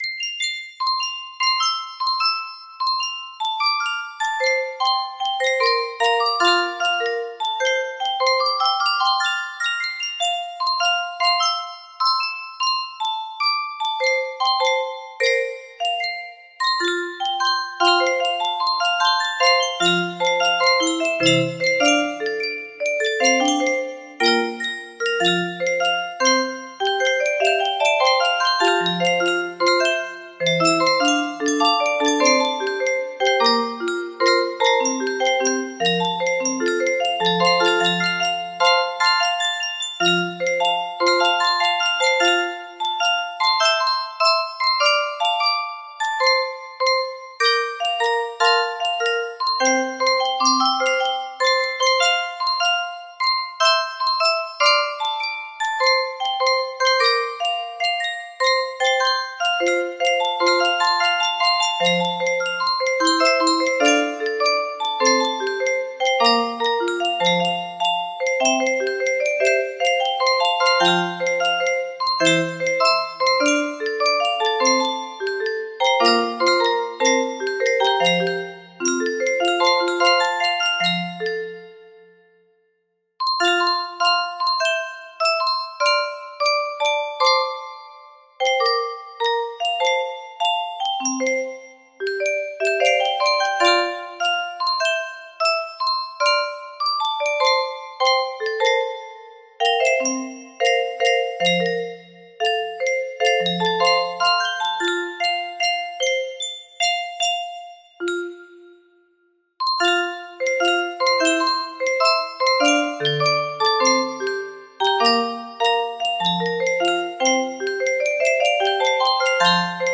Full range 60
Done, Proper tempo, No Reds. Enjoy.